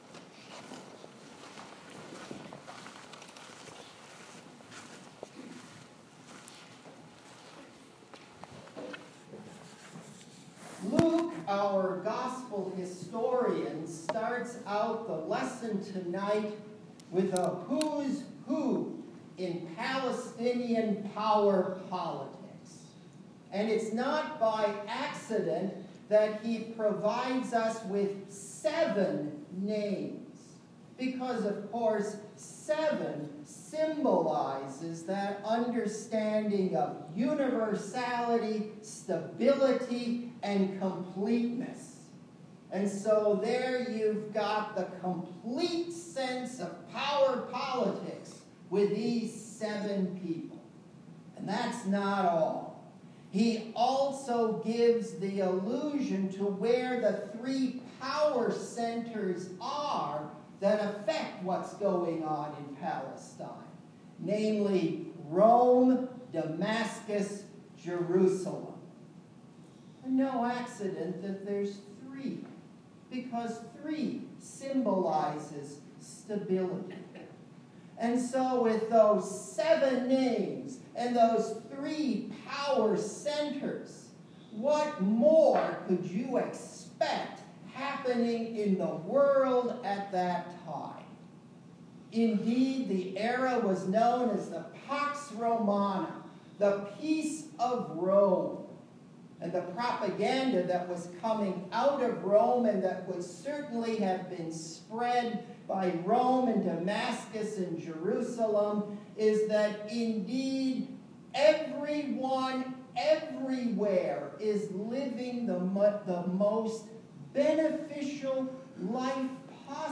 SECOND SUNDAY OF ADVENT Dec. 9, 2018